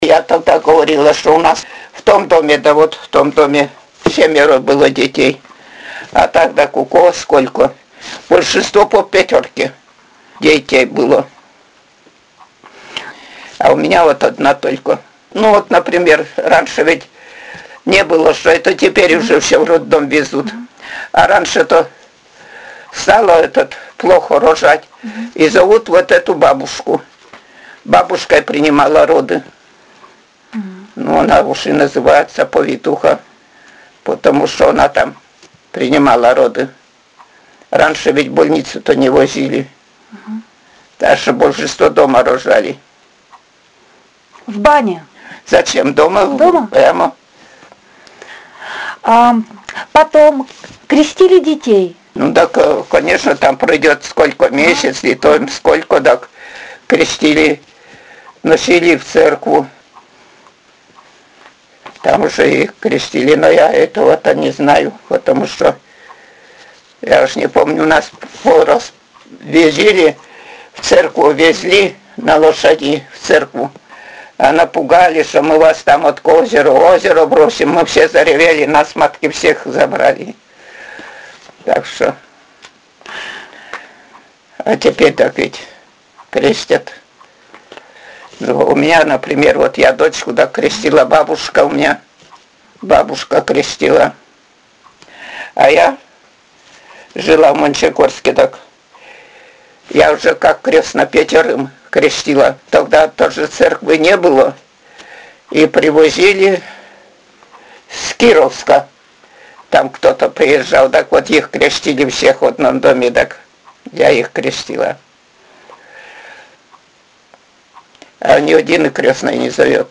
«Я тогда говорила, что у нас в том доме, да вот в том доме семеро было детей…» — Говор северной деревни
Пол информанта: Жен.
Место записи: Плах.
Аудио- или видеозапись беседы: